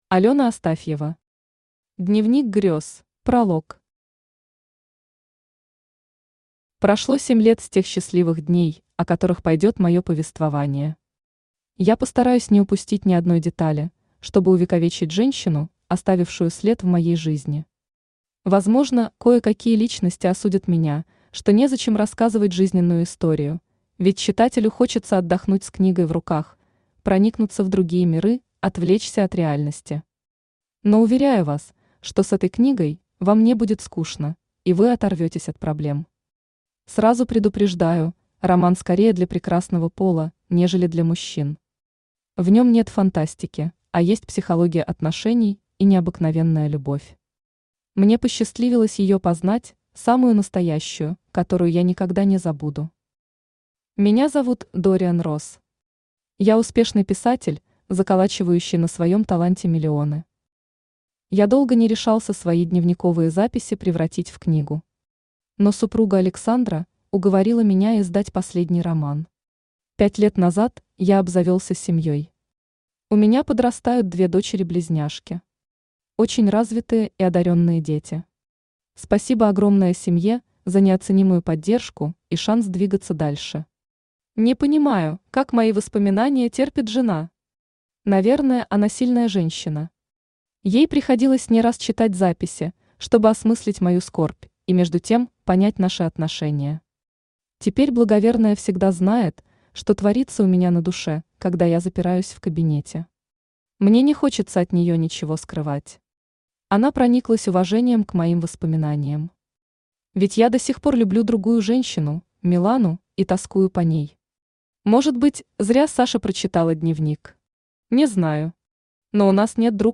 Аудиокнига Дневник грёз | Библиотека аудиокниг
Aудиокнига Дневник грёз Автор Алёна Астафьева Читает аудиокнигу Авточтец ЛитРес.